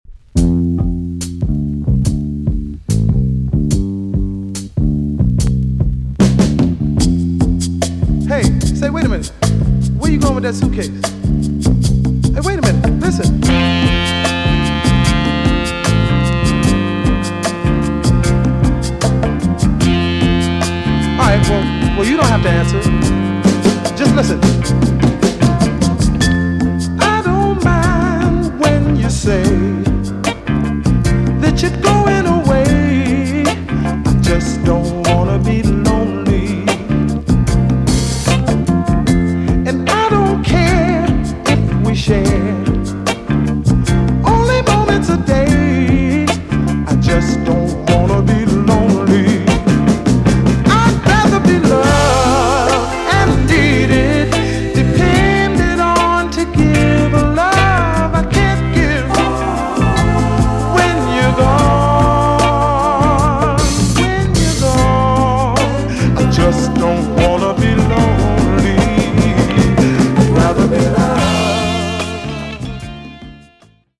TOP >JAMAICAN SOUL & etc
EX- 音はキレイです。